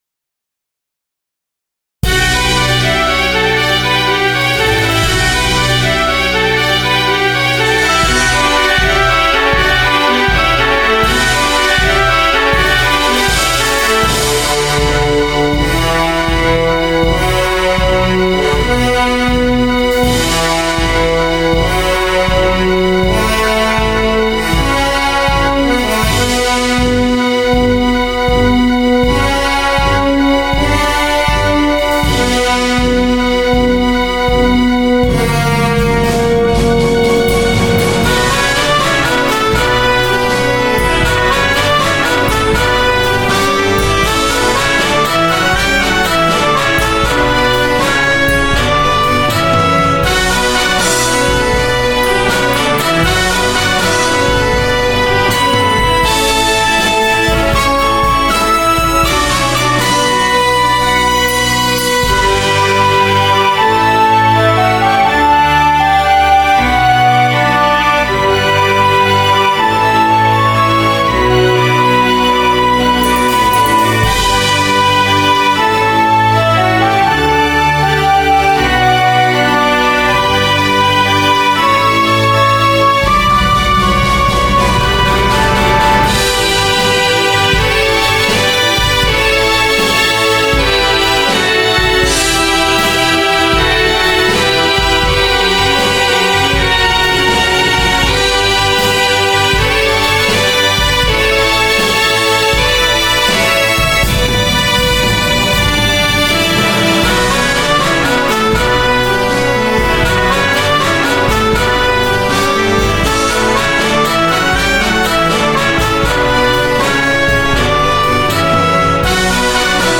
基本的には、あまり変わってないのですが、音色が増えてより豪華になっています。
力強いホルンの音色がお気に入り。